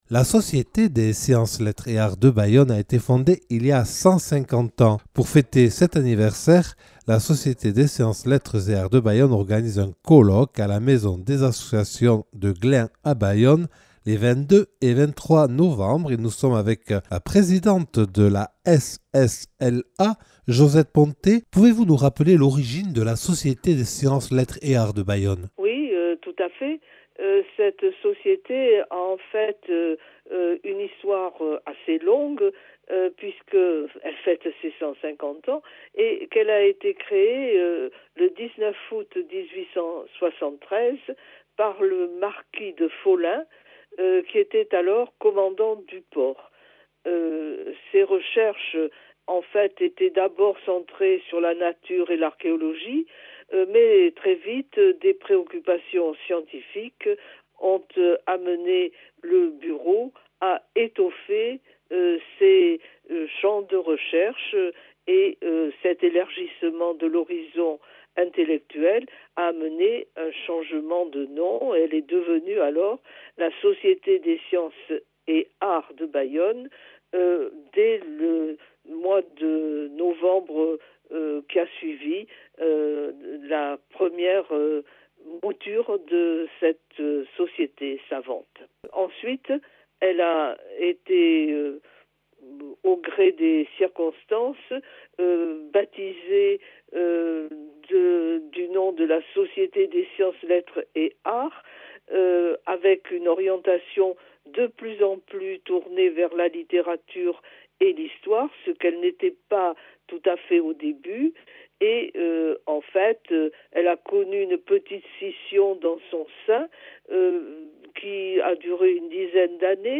Accueil \ Emissions \ Infos \ Interviews et reportages \ La Société des Sciences Lettres et Arts de Bayonne célèbre son 150ème (...)